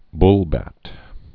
(blbăt)